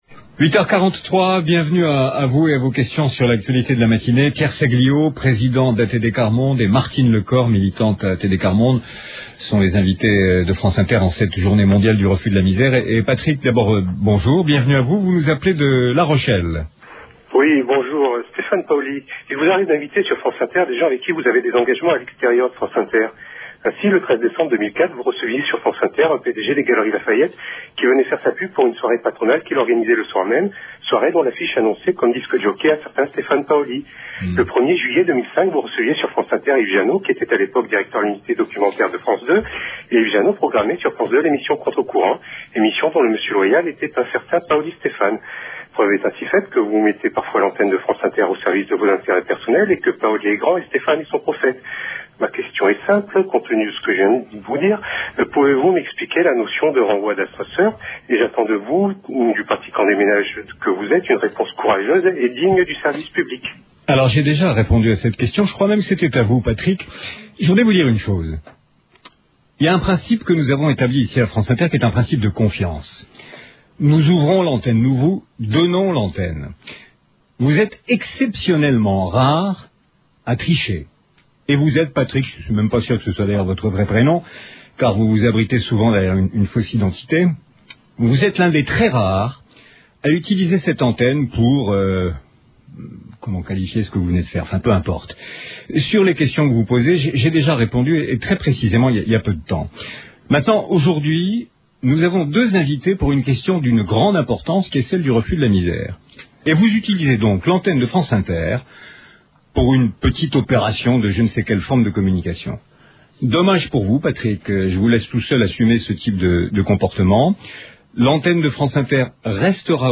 Mais, lors de son passage à l’antenne, la question - embarrassante - est toute autre.